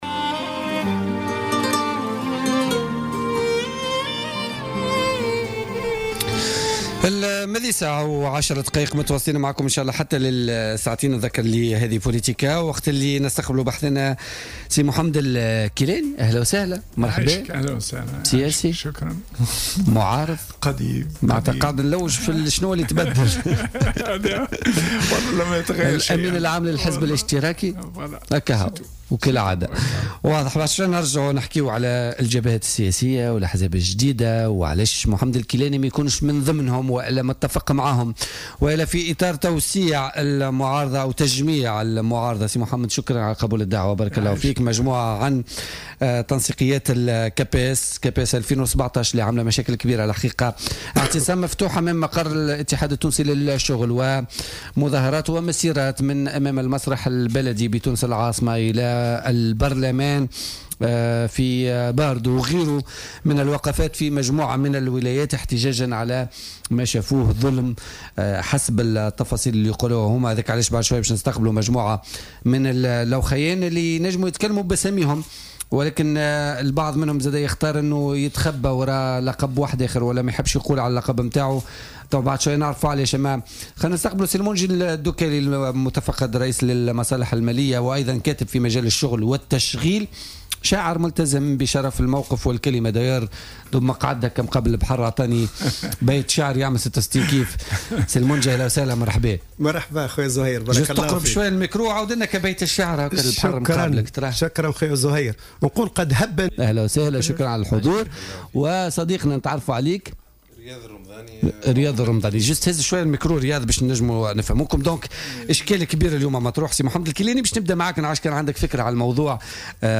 خصّص برنامج "بوليتيكا" على "الجوهرة أف أم" اليوم الثلاثاء منبرا لنقاش تطورات مناظرة أساتذة التعليم الثانوي التي اصطلح على تسميتها بـ "الكاباس".